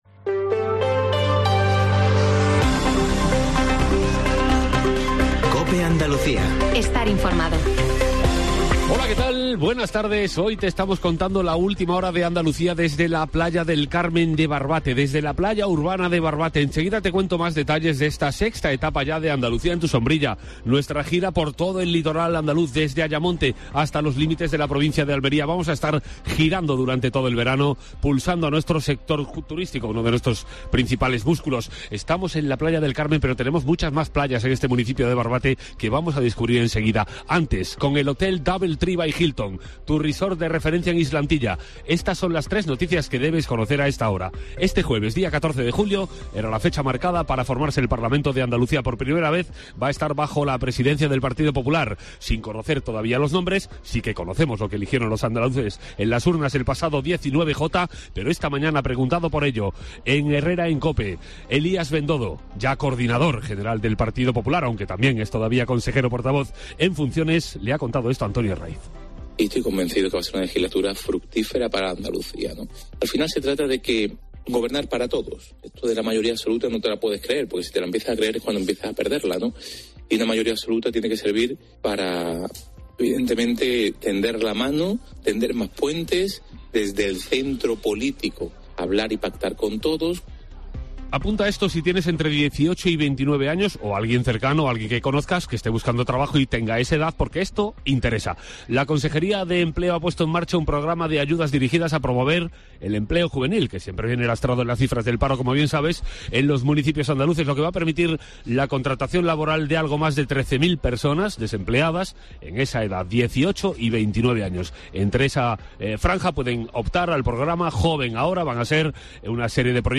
Cope Andalucía en tu sombrilla desde la Playa del Carmen de Barbate